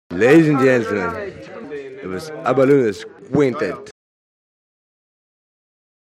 Her har vi et amatøropptak
Varierende og allsidig fest og danse -musikk.